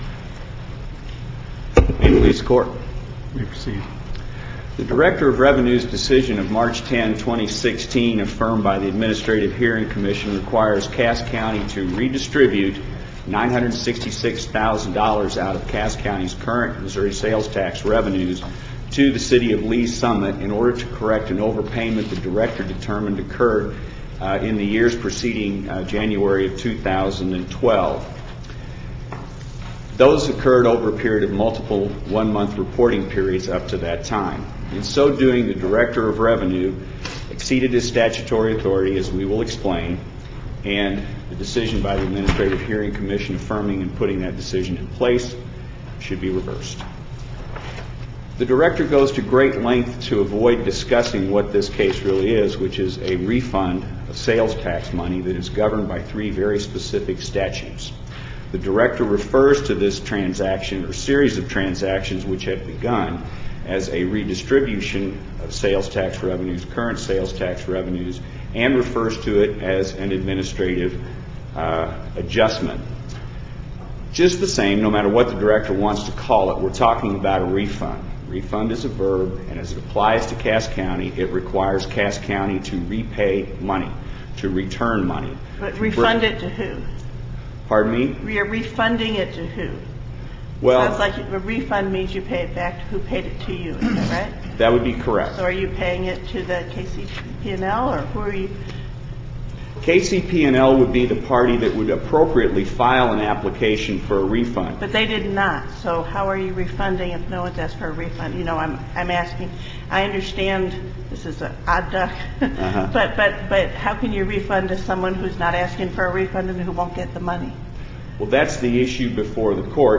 MP3 audio file of oral arguments in SC96731